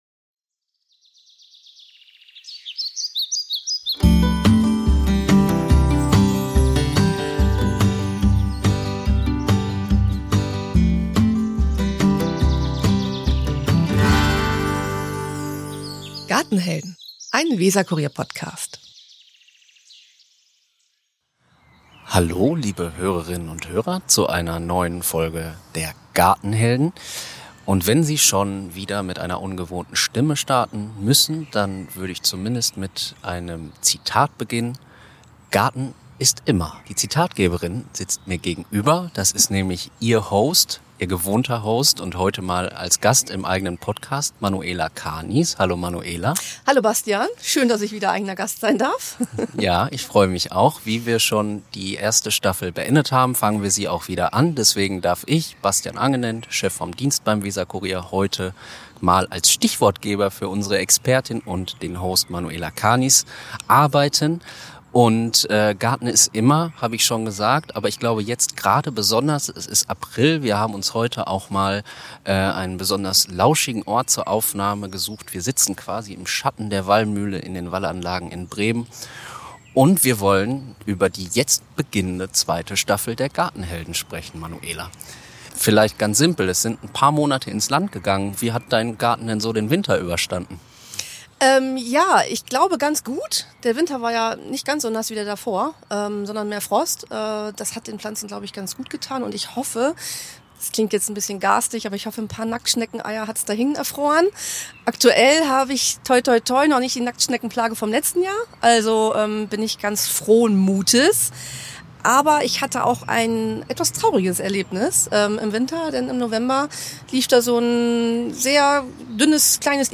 interviewt Menschen, die leidenschaftlich gerne gärtnern – beruflich oder als Hobby. Hörerinnen und Hörer bekommen Basiswissen zum Gärtnern geliefert, aber es geht auch um die eigenen Vorlieben: Wie kann ich mein grünes Paradies so gestalten, dass es mir gefällt – aber auch so, dass es sinnvoll für die Natur ist?